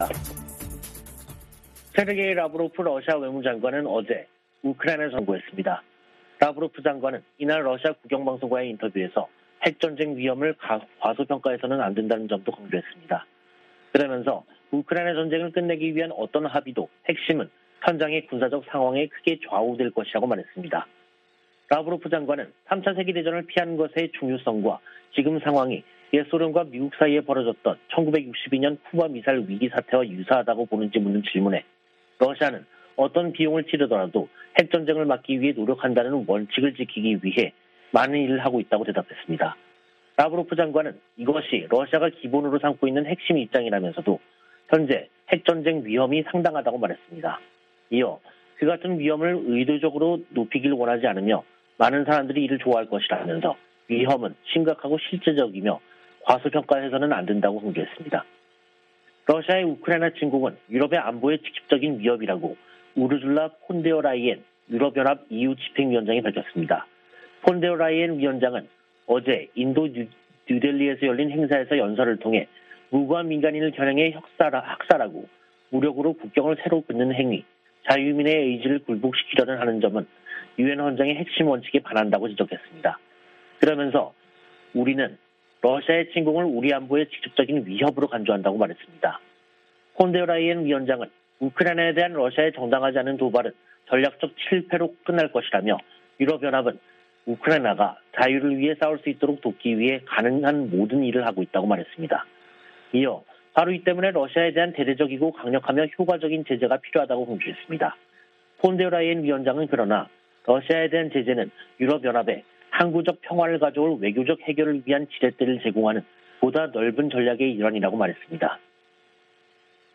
VOA 한국어 간판 뉴스 프로그램 '뉴스 투데이', 2022년 4월 26일 2부 방송입니다. 북한이 25일 핵 무력을 과시하는 열병식을 개최했습니다.